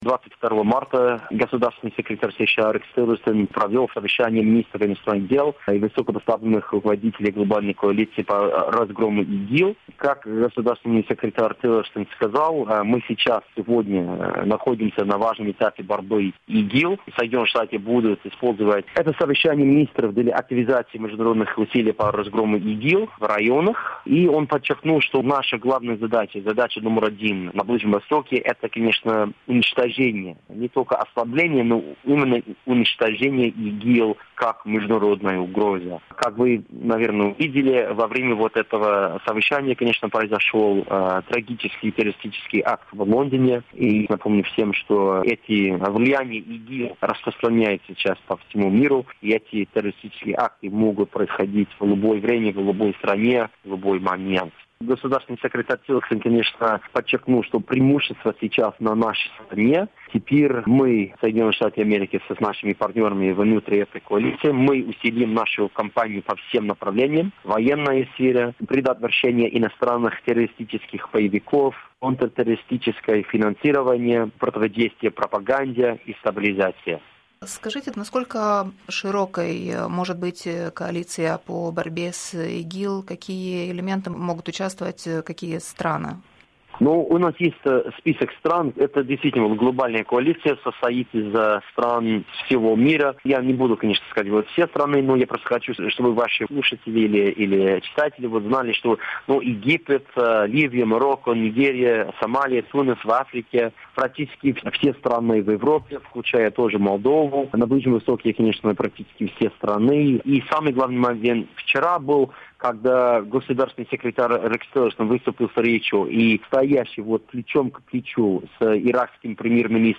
Interviu cu purtătorul de cuvânt al Departamentului de Stat american.